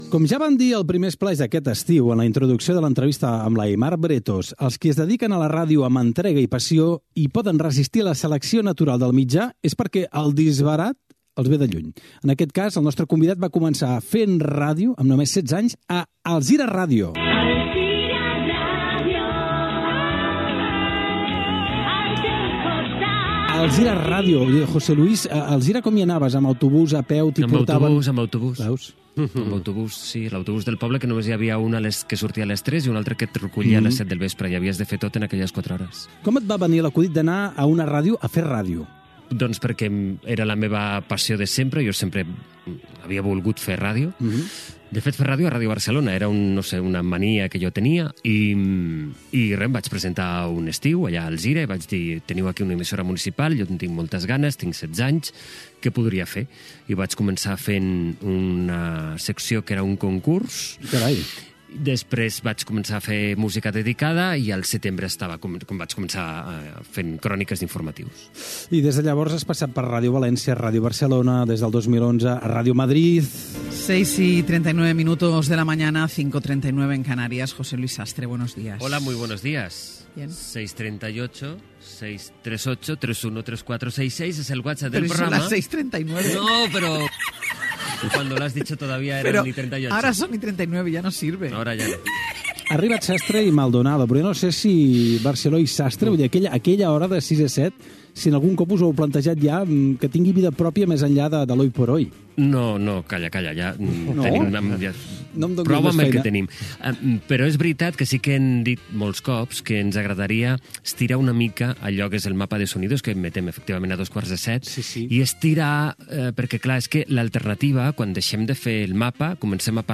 Fragment d'una entrevista al periodista José Luis Sastre sobre els seus inicis a la ràdio i la seva trajectòria professional
Entreteniment